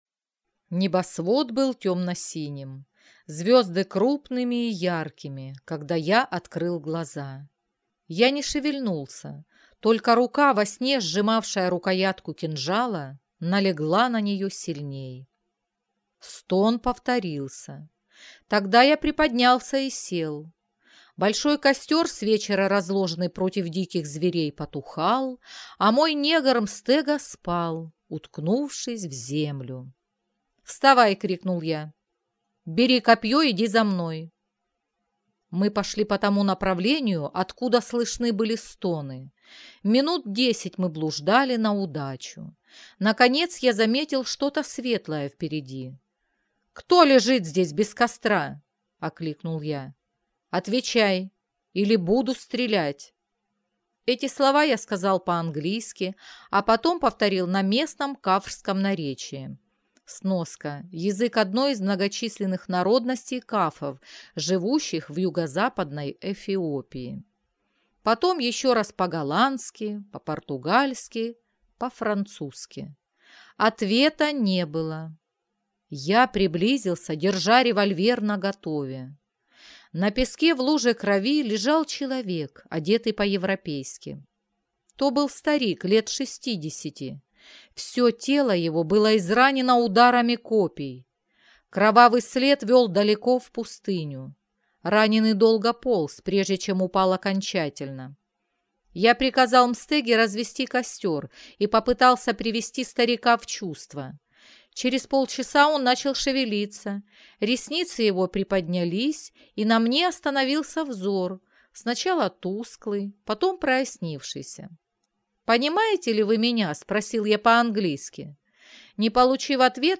Аудиокнига Гора Звезды | Библиотека аудиокниг